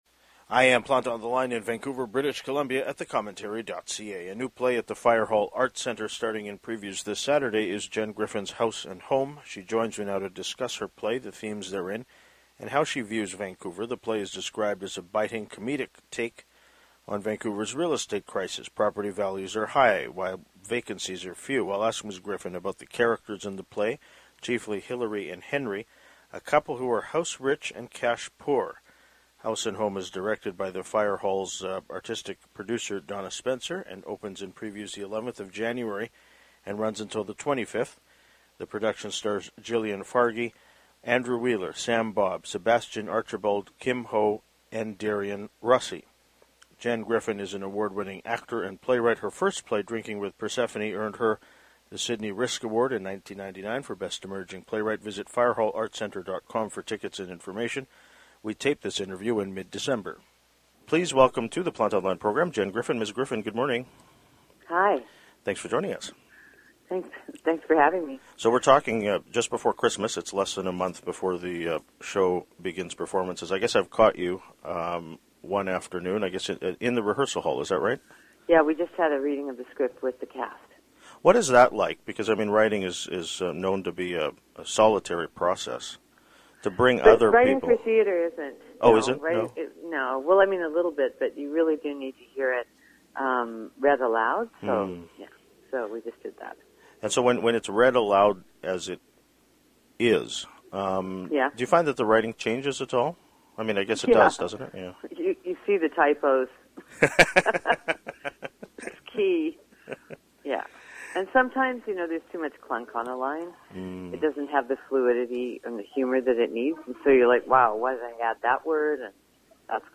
We taped this interview in mid-December.